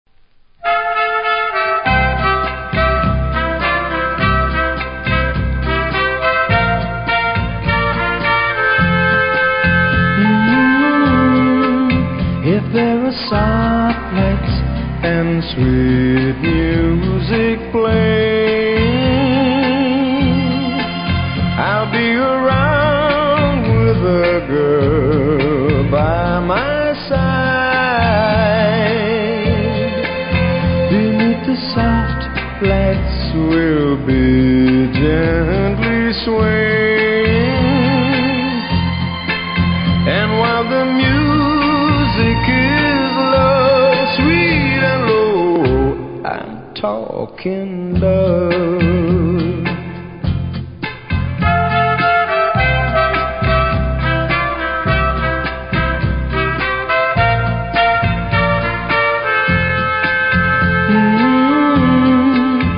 うすキズ多めですが音は良好なので試聴で確認下さい。